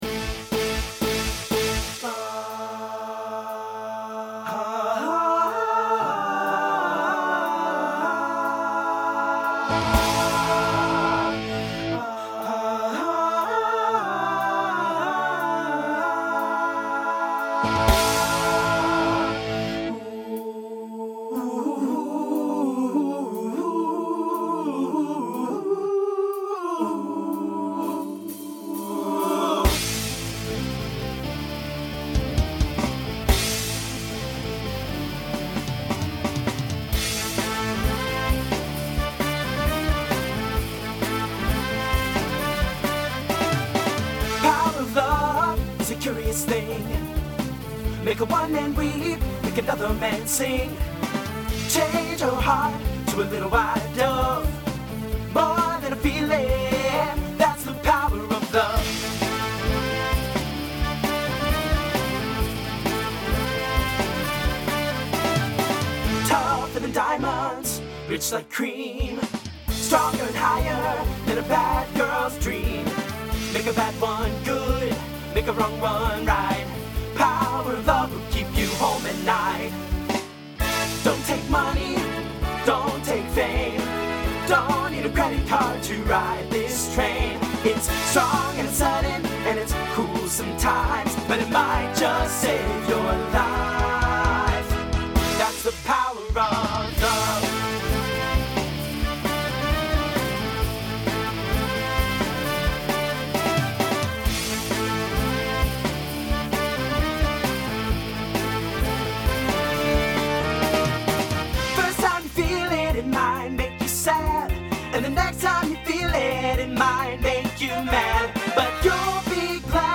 TTB/SSA
Voicing Mixed Instrumental combo Genre Rock